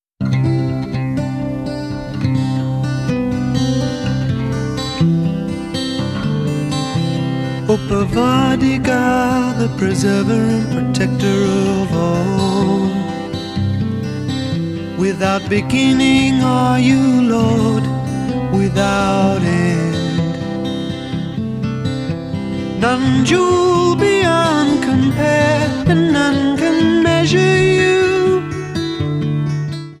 The 2006 CDs sound a bit louder.